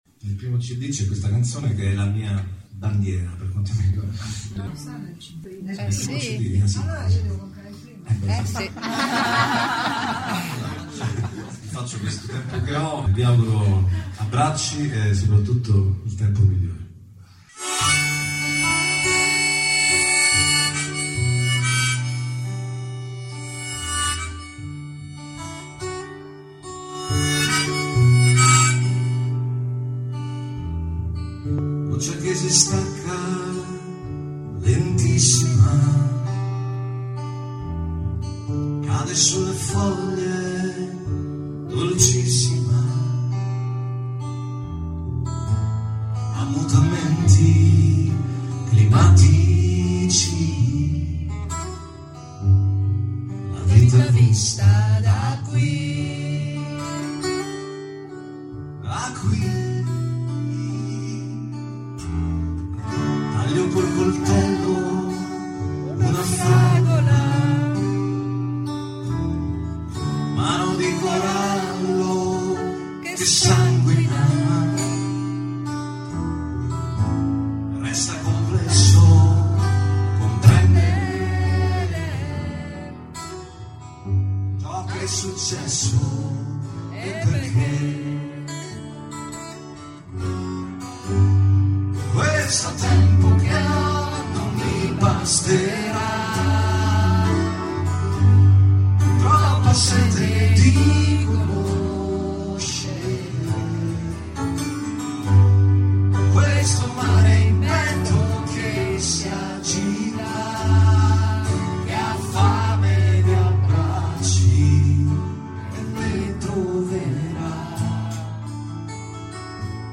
ROMA, "Teatro Arciliuto"
chitarra acustica
fisarmonica, piano